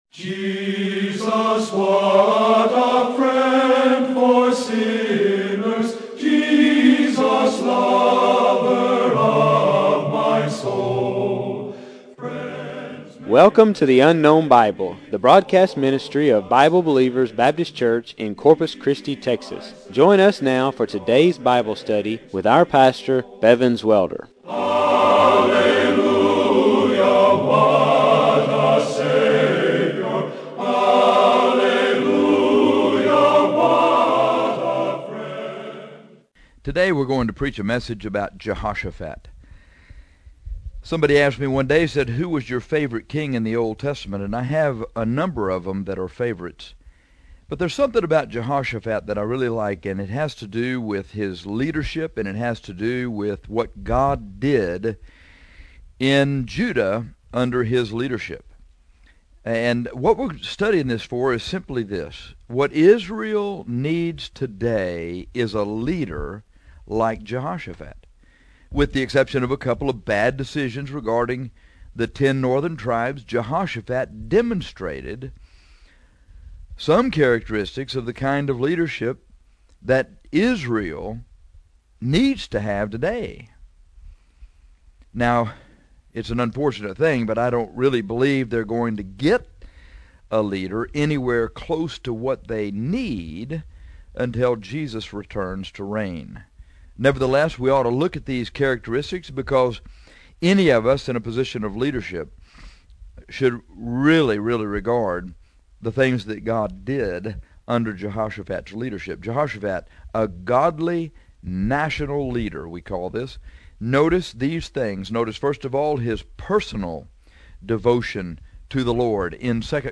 This broadcast is about Jehoshaphat a Godly Leader.